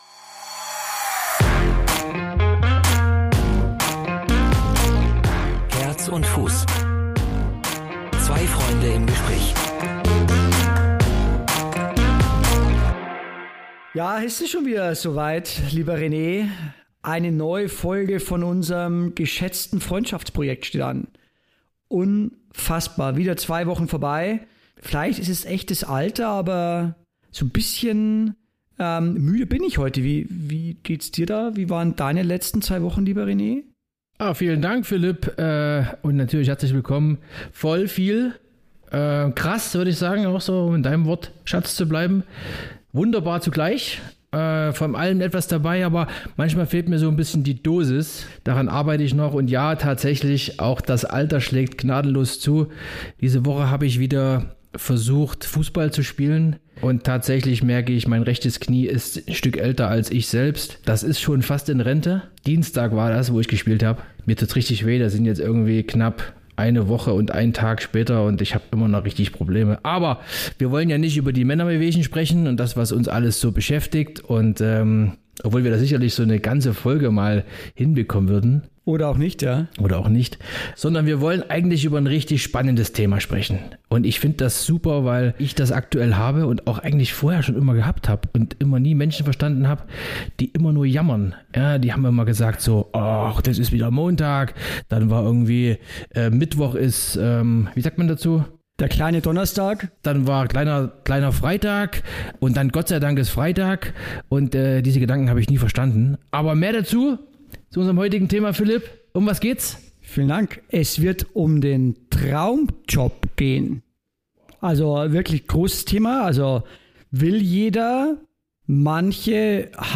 Unsere heutige Gästin ist Coachin und “Erste-Hilfe-Fachfrau” in Sachen Existenzgründung.